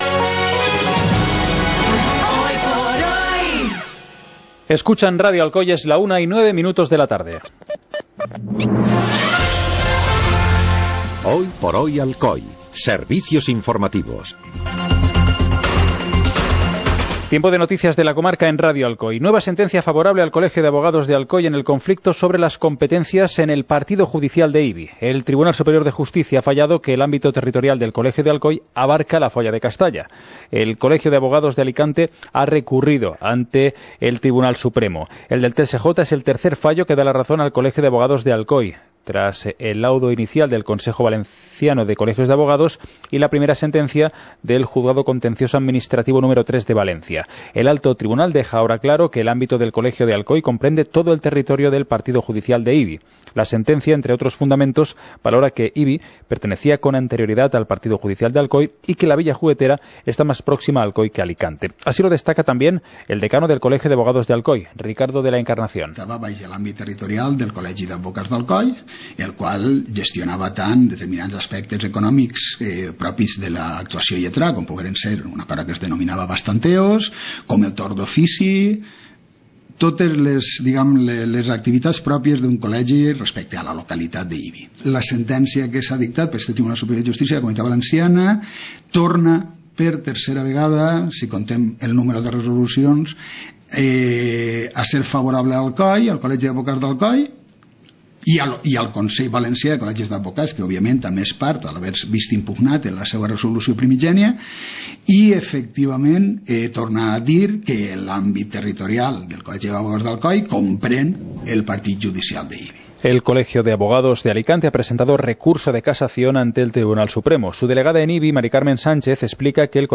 Informativo comarcal - miércoles, 06 de septiembre de 2017